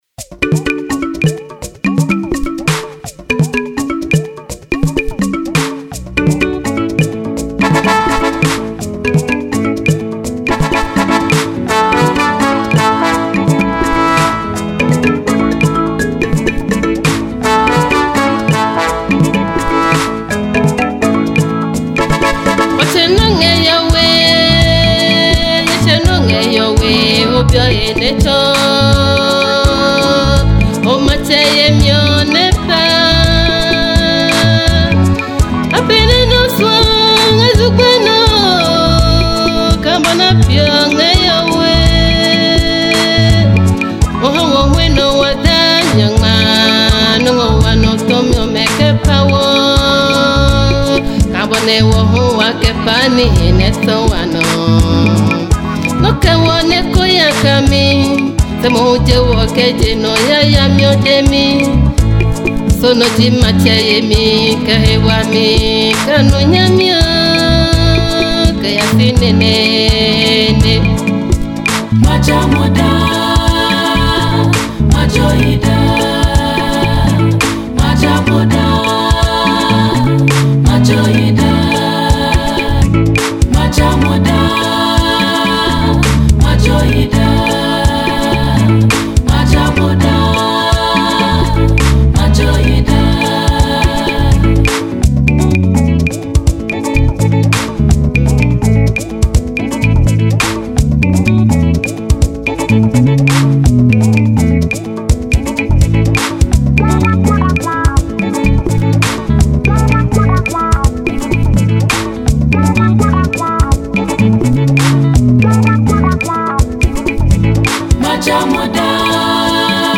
Gospel
Ghanaian female Gospel singer